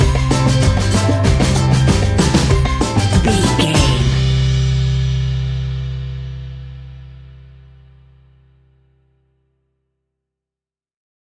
Island summer sunshine music!
That perfect carribean calypso sound!
Ionian/Major
steelpan
drums
bass
brass
guitar